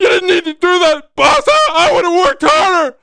WELDER-GETSHOT2.mp3